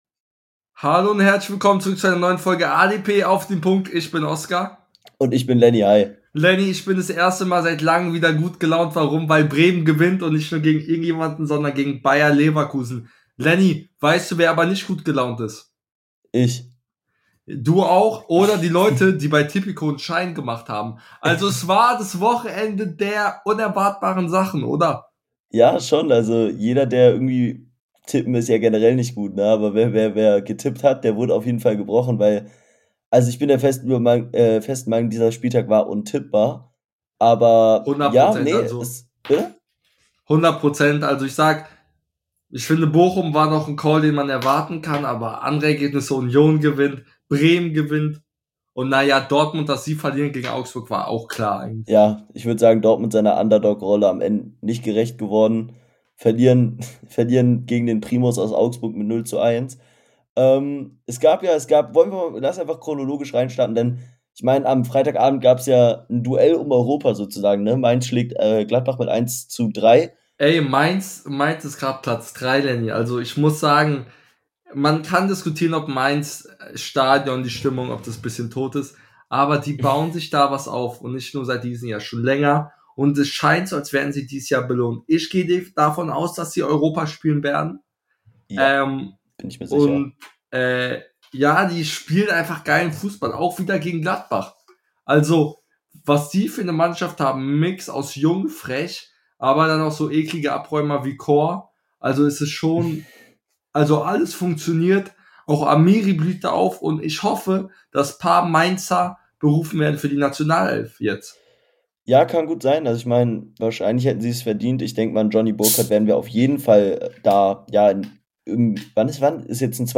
In der heutigen Folge reden die beiden Hosts über diesen verrückten Spieltag , zerlegen den BVB , glauben an den HSV und vieles mehr